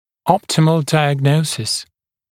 [‘ɔptɪməl ˌdaɪəg’nəusɪs][‘оптимэл ˌдайэг’ноусис]оптимальный диагноз